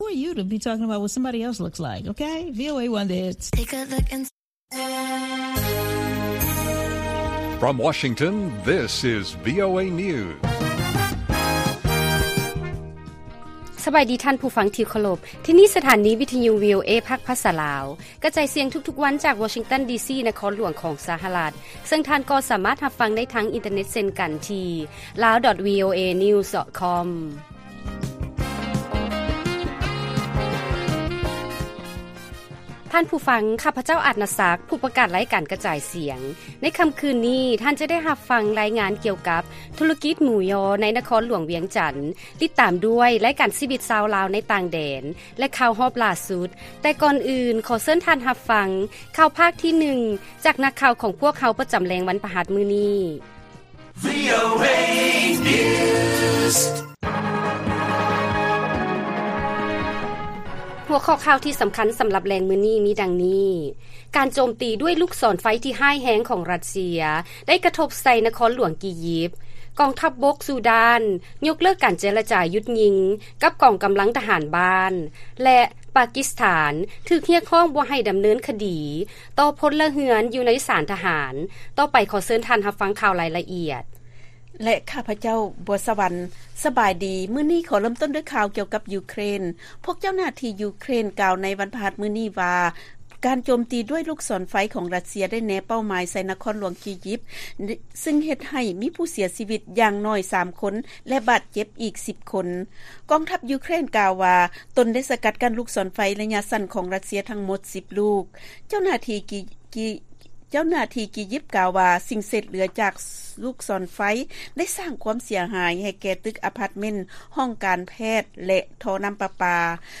ລາຍການກະຈາຍສຽງຂອງວີໂອເອ ລາວ: ການໂຈມຕີດ້ວຍລູກສອນໄຟທີ່ຮ້າຍແຮງຂອງຣັດເຊຍໄດ້ກະທົບໃສ່ນະຄອນຫຼວງກີຢິບ